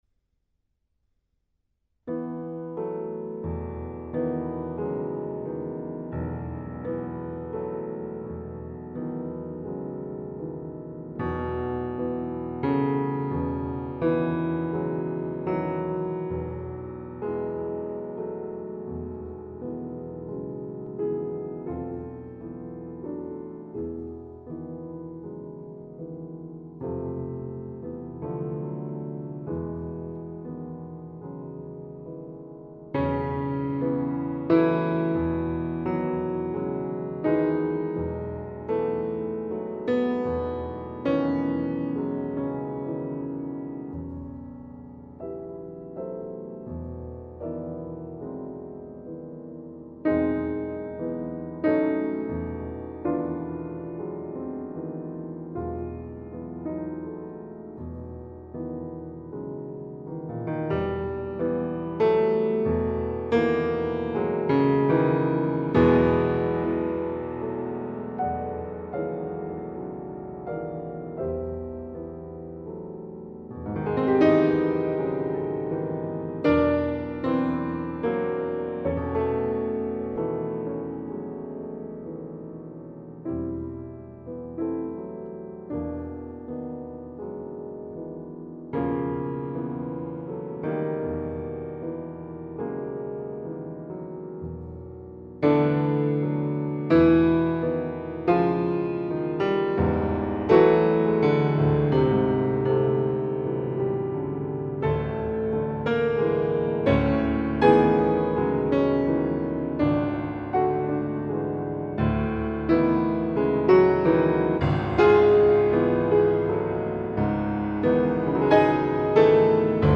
Original piano compositions and classical interpretations
Recorded in the historic Holywell Music Room, Oxford